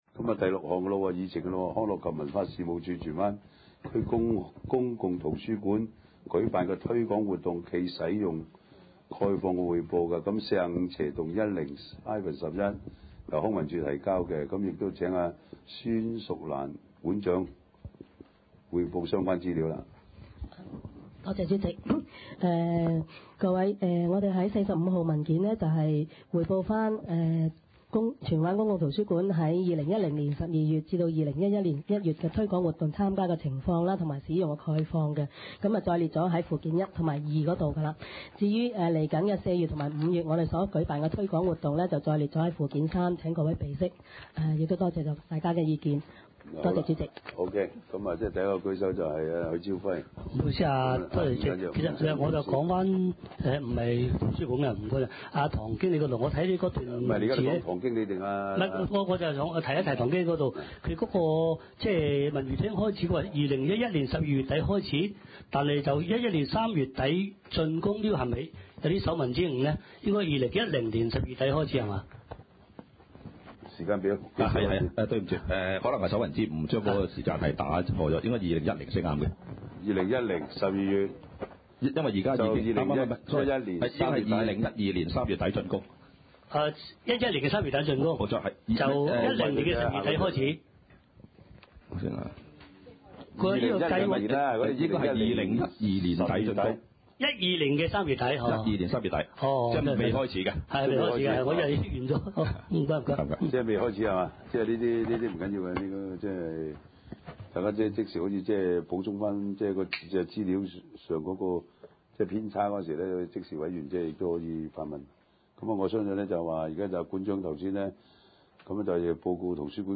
地區設施管理委員會第二十次會議
荃灣民政事務處會議廳